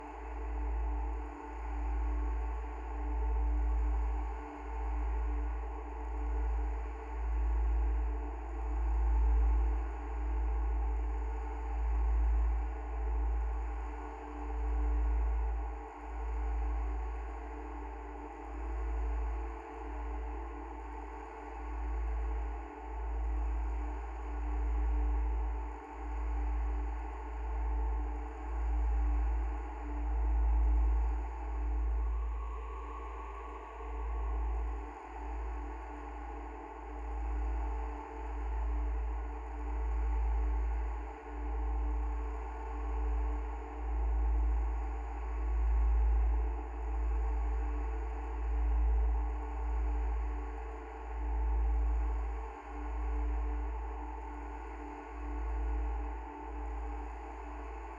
Dungeon_Loop_02.wav